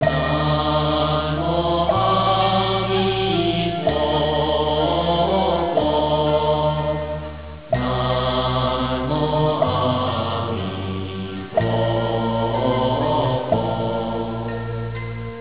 The Buddhist mantra
which repeats "in perpetuo" at funerals and night markets. encoded by your webmaster